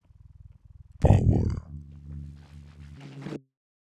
• demonic techno voice POWER 125.wav
Changing the pitch and transient for a studio recorded voice (recorded with Steinberg ST66), to sound demonic/robotic.
demonic_techno_voice_POWER_125-2_jis.wav